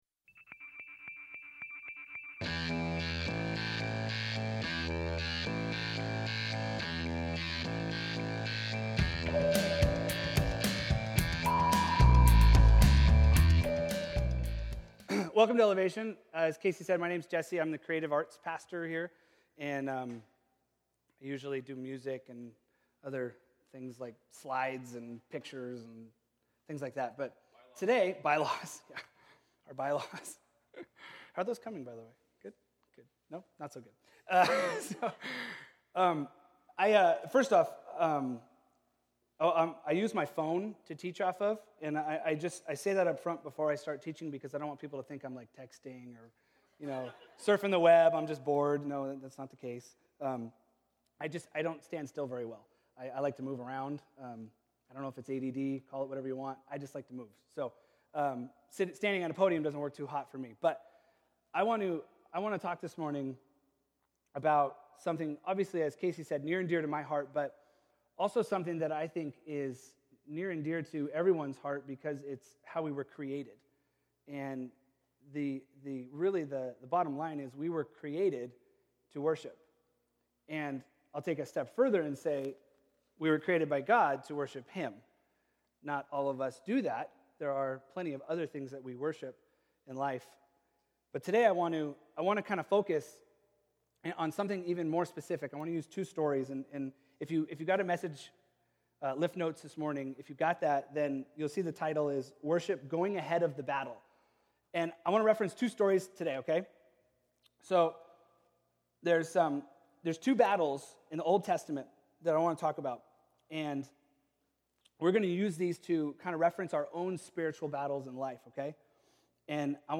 A message on Worship and going ahead of the spiritual battle through faith and obedience.